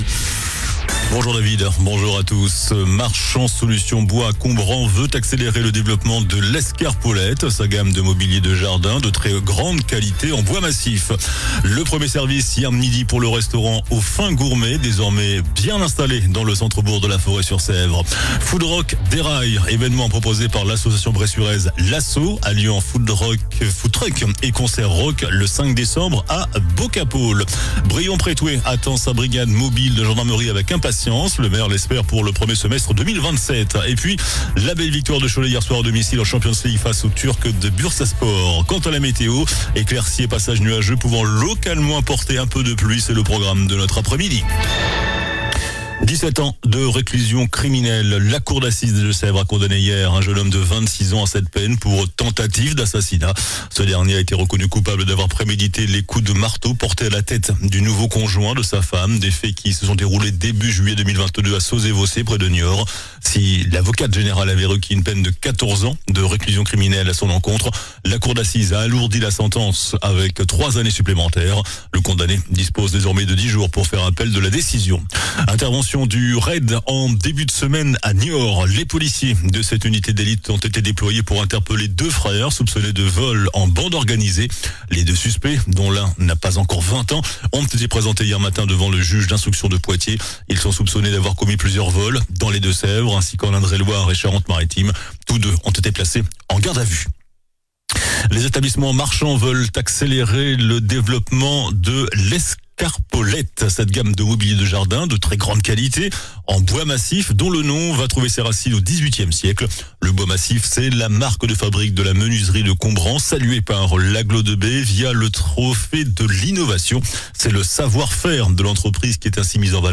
JOURNAL DU JEUDI 20 NOVEMBRE ( MIDI )